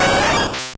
Cri de Pyroli dans Pokémon Or et Argent.